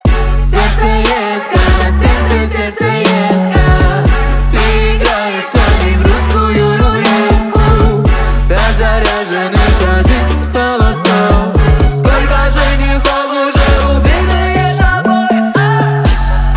• Качество: 321, Stereo
громкие
русский рэп
мощные басы
качающие